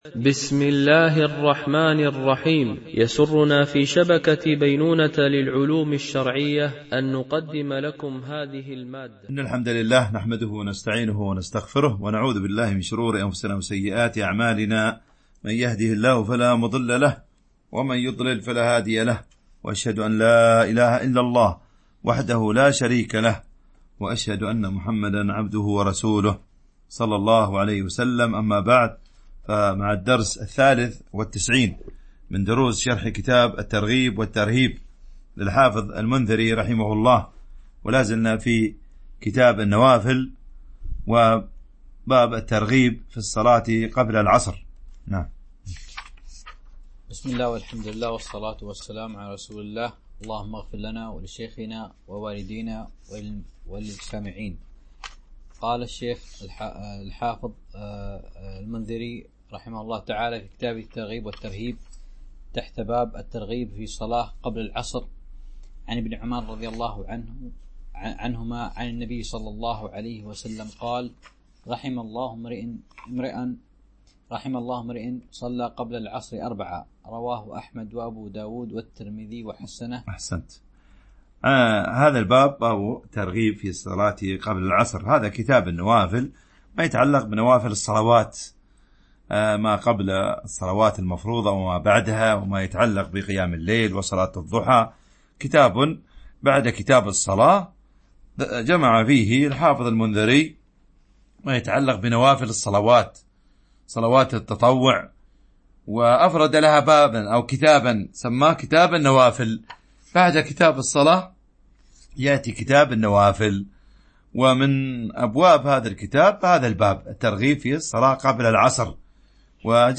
شرح كتاب الترغيب والترهيب - الدرس 93 ( كتاب النوافل.الحديث 861 - 881 )